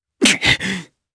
Lucias-Vox_Damage_jp_02.wav